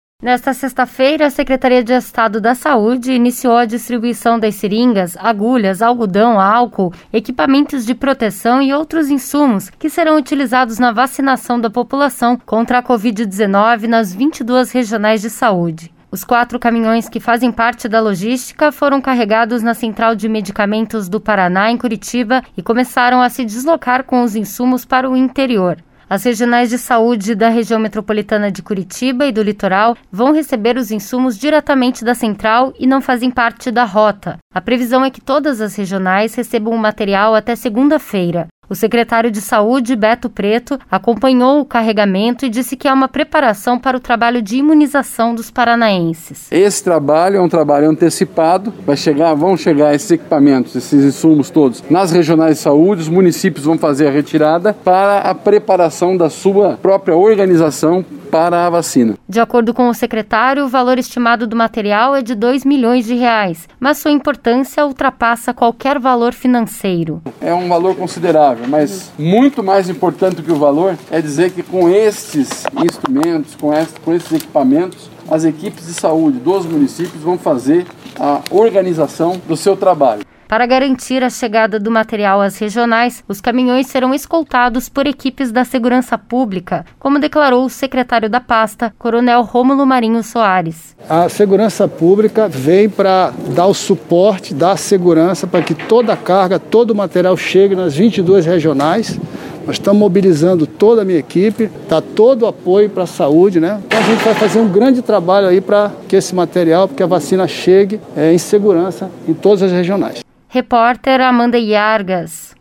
O secretário de Saúde, Beto Preto, acompanhou o carregamento e disse que é uma preparação para o trabalho de imunização dos paranaenses.